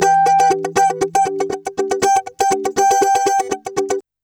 120FUNKY06.wav